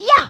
saute_champi.mp3